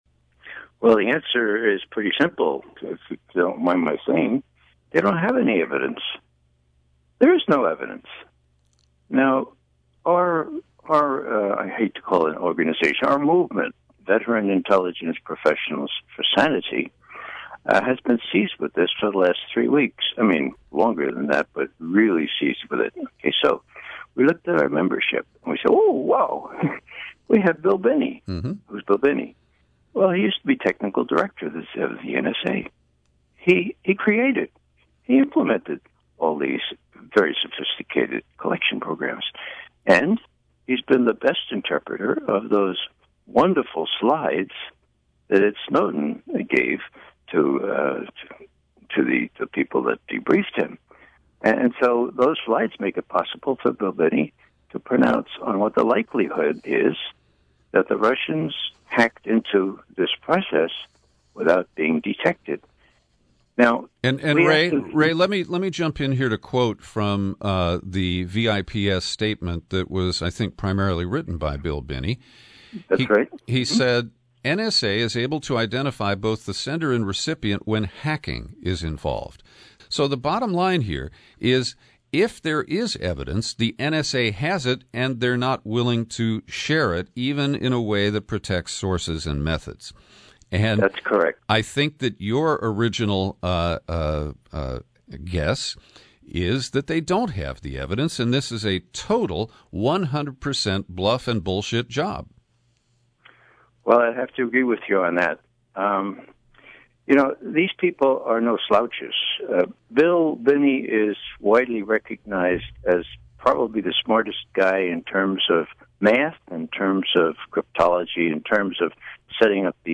In-Depth Interview: Ex-CIA Analyst Ray McGovern Debunks “Russia Hacking” Beltway Fiction